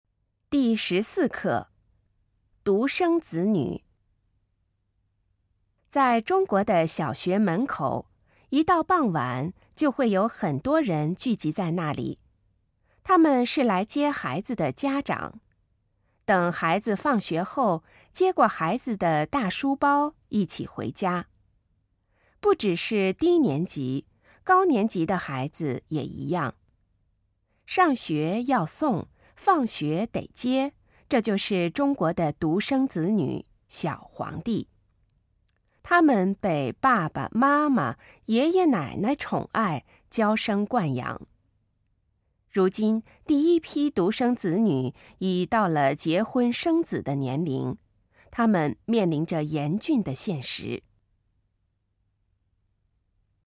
ネイティブスピード版です。